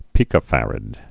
(pēkə-fărəd, -ăd, pī-)